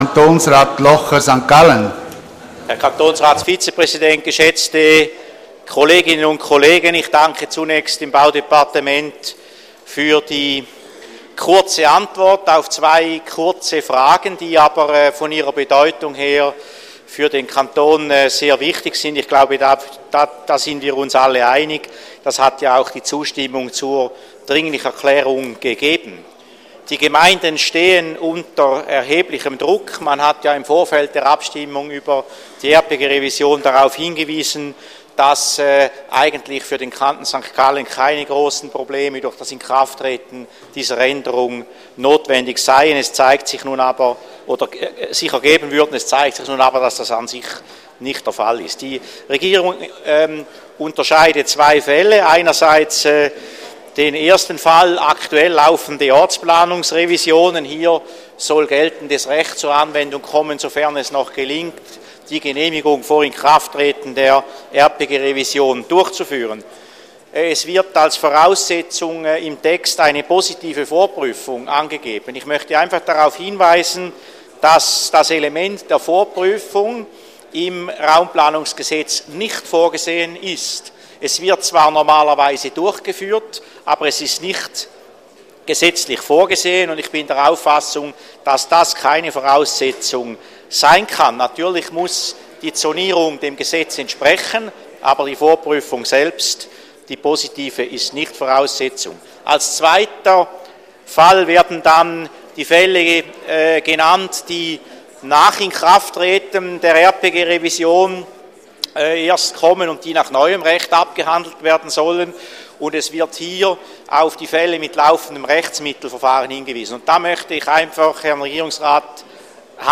25.2.2014Wortmeldung
Session des Kantonsrates vom 24. und 25. Februar 2014